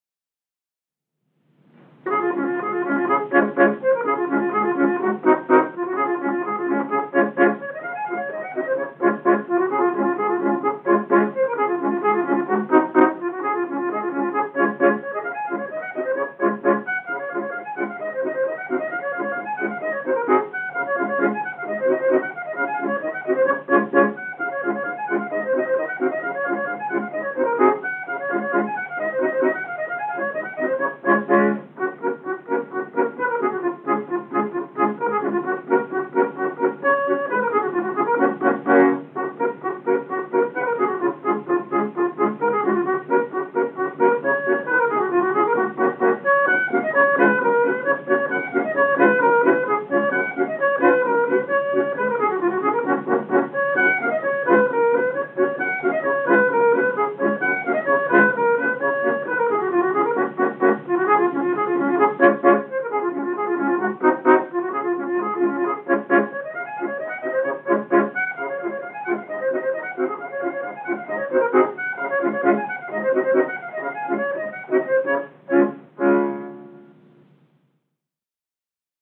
Maccann Duet